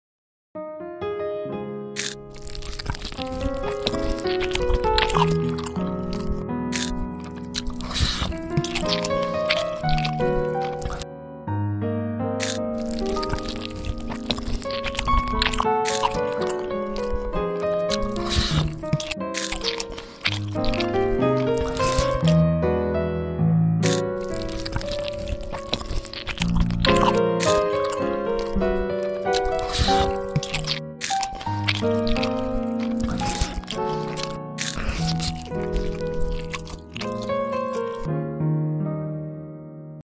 Asmr Mukbang Animation